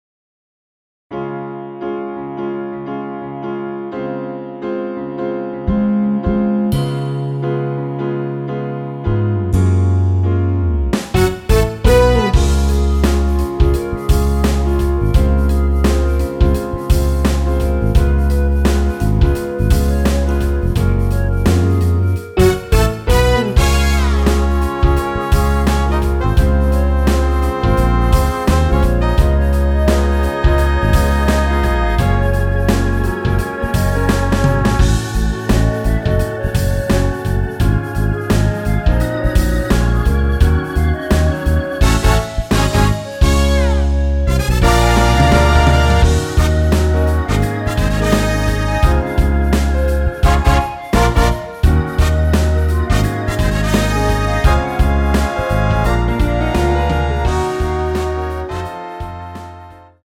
원키 멜로디 포함된 MR입니다.
Db
앞부분30초, 뒷부분30초씩 편집해서 올려 드리고 있습니다.
중간에 음이 끈어지고 다시 나오는 이유는